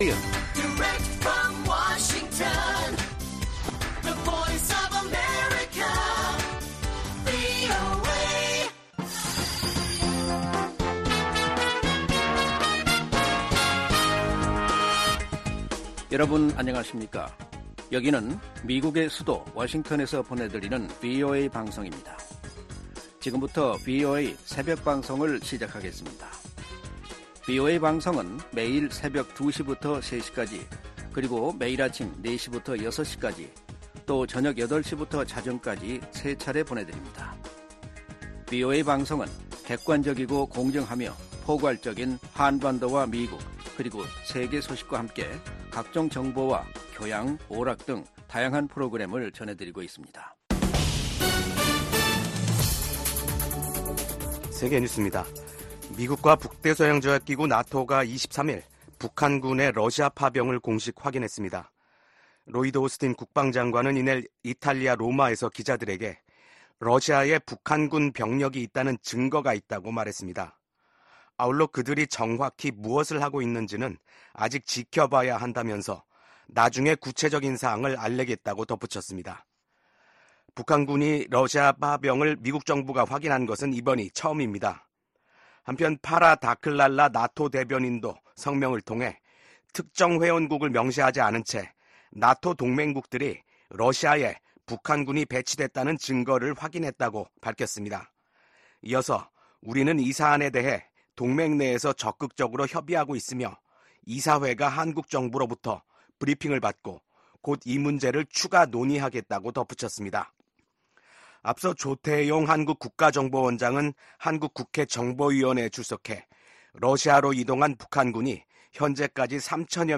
VOA 한국어 '출발 뉴스 쇼', 2024년 10월 24일 방송입니다. 국무부는 한국이 우크라이나에 무기지원을 검토할 수 있다고 밝힌 데 대해 모든 국가의 지원을 환영한다는 입장을 밝혔습니다. 미국 하원의원들이 북한이 러시아를 지원하기 위해 특수부대를 파병했다는 보도에 깊은 우려를 표했습니다.